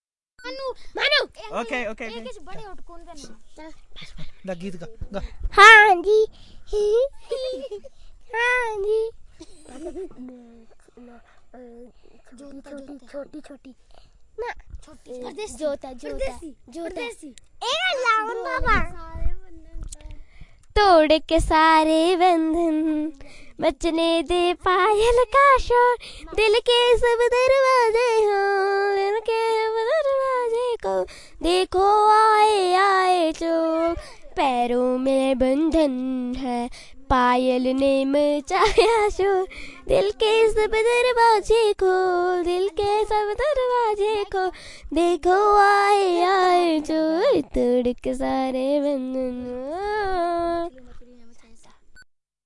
描述：在印度达姆萨拉录制的2002年。孩子们演唱电影中的歌曲和儿童歌曲。
Tag: 宝莱坞 印度 孩子